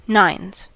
Ääntäminen
Ääntäminen US : IPA : [ˈnaɪnz] Haettu sana löytyi näillä lähdekielillä: englanti Käännöksiä ei löytynyt valitulle kohdekielelle. Nines on sanan nine monikko.